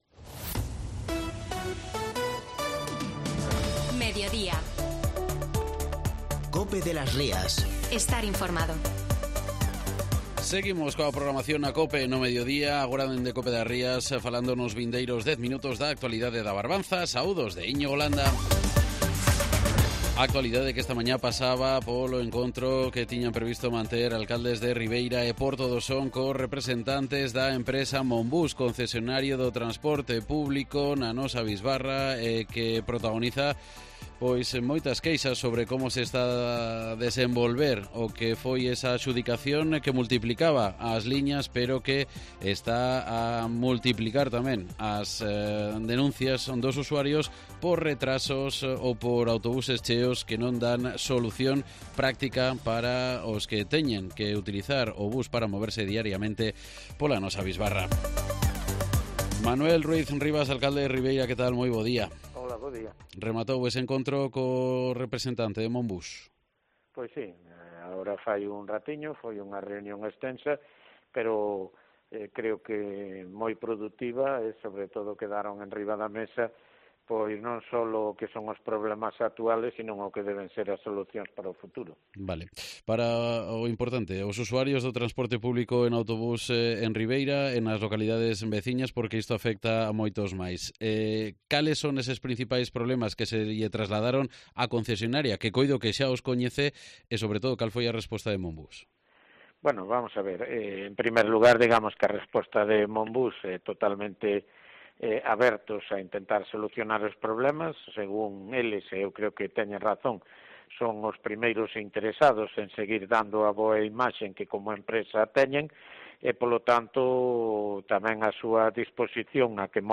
Entrevista con el alcalde de Ribeira, Manuel Ruiz Rivas, tras el encuentro con Monbús